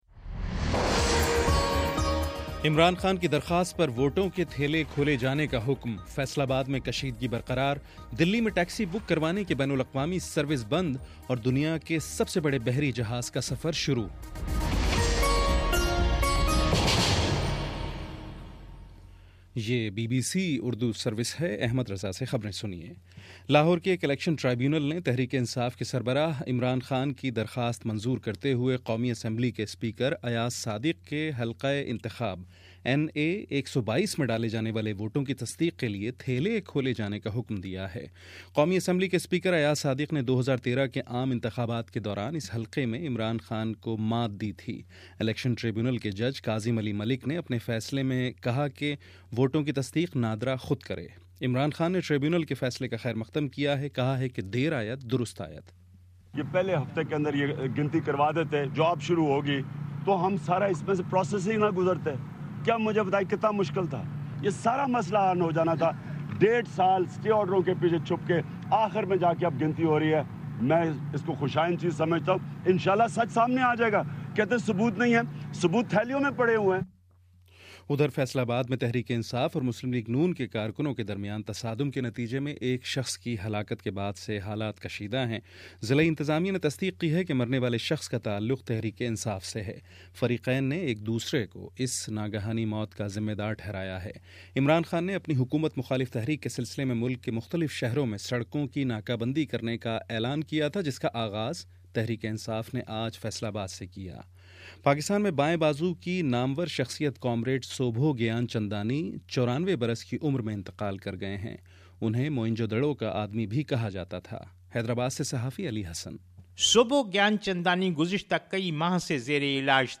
دسمبر08: شام سات بجے کا نیوز بُلیٹن